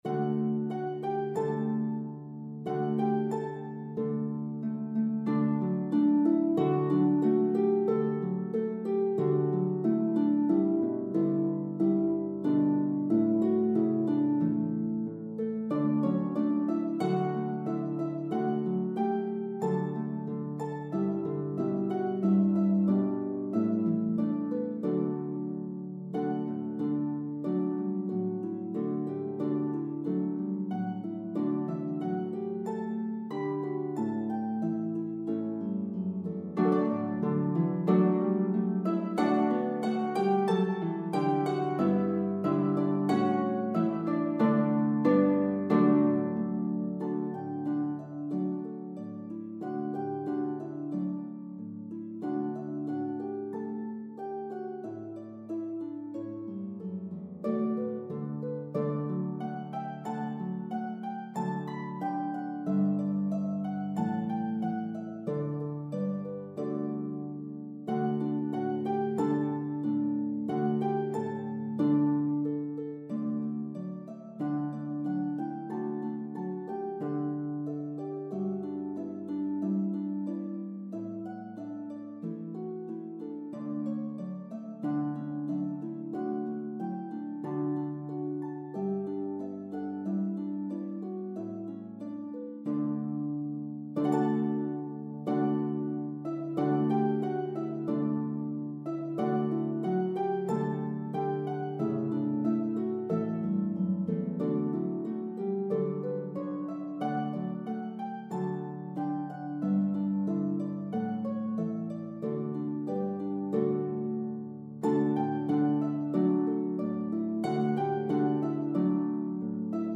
Harp I – 6 pages
Harp II – 6 pages
Dynamics are clearly marked.